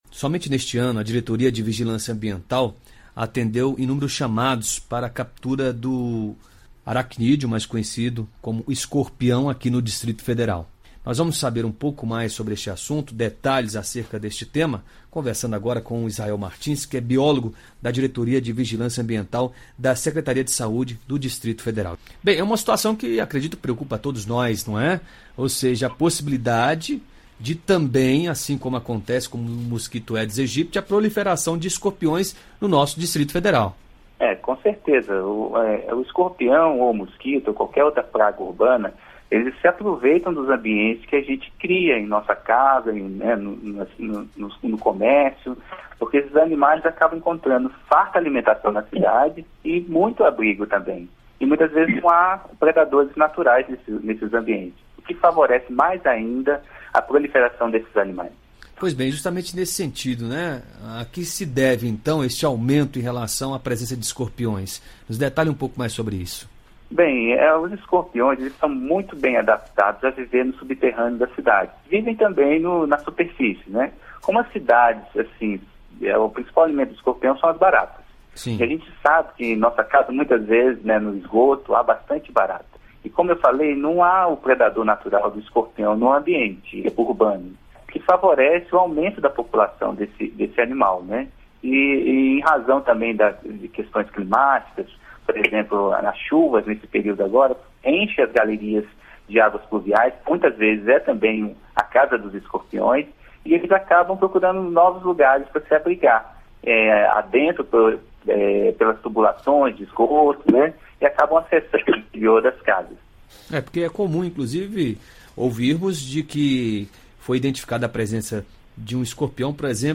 Entrevista: Aprenda a evitar escorpiões